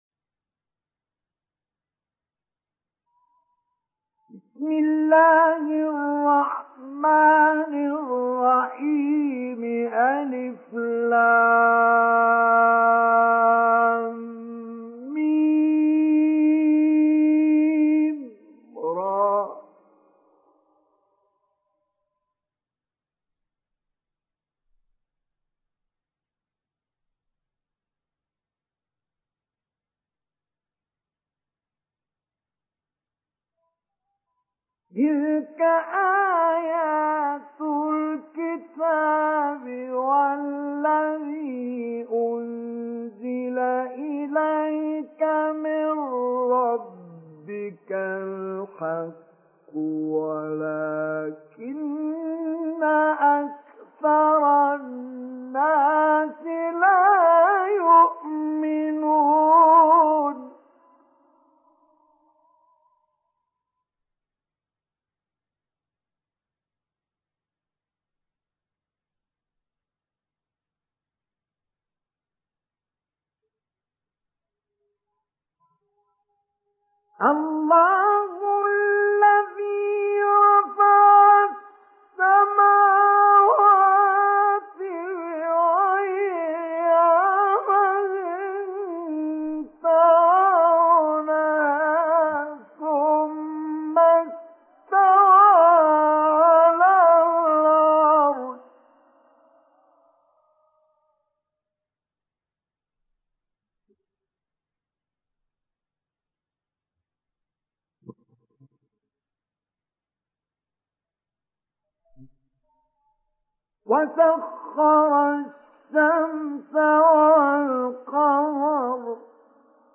Surat Ibrahim Download mp3 Mustafa Ismail Mujawwad Riwayat Hafs dari Asim, Download Quran dan mendengarkan mp3 tautan langsung penuh
Download Surat Ibrahim Mustafa Ismail Mujawwad